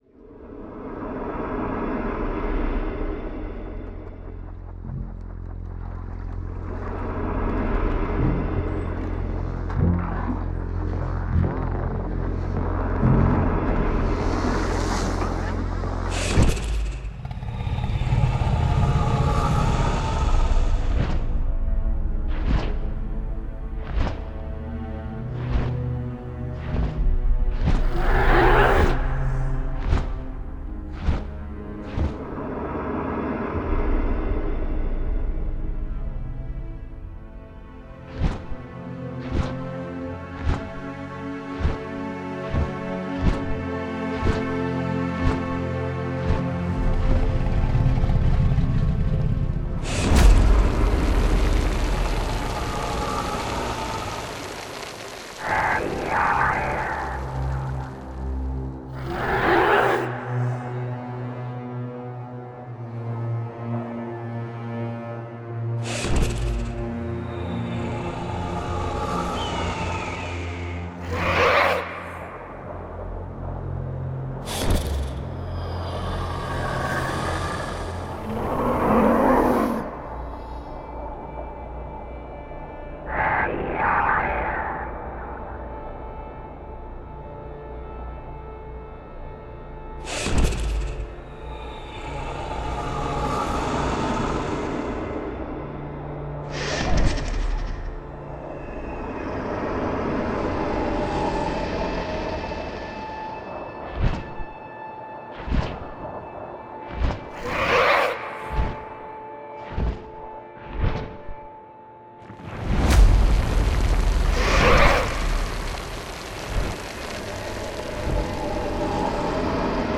Звуковые инсталляции, наполняющие территорию Музея-заповедника «Казанский Кремль», окликают современность из прошлого, вторят пестрому многоголосию истории.
Прослушайте звуки и окажитесь рядом с огнедышащим драконом!